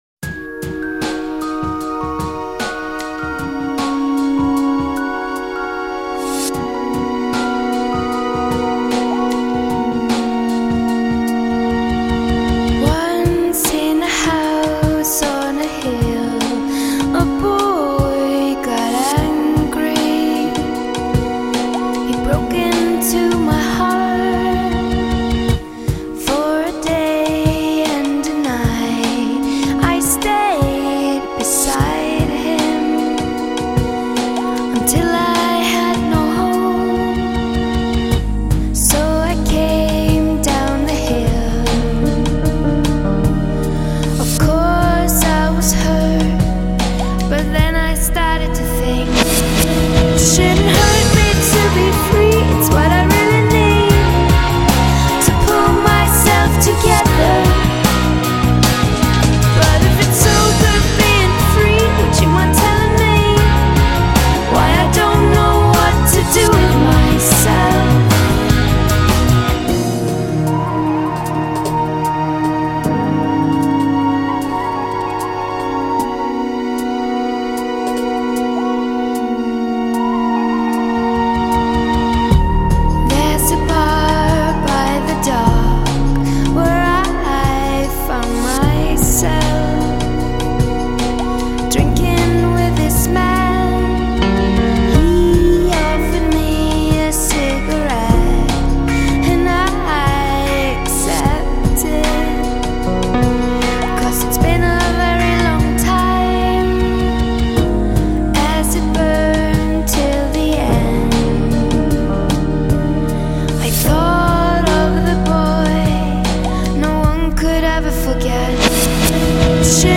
Dance, Electronica R&B, Rock / Pop, Dance Collections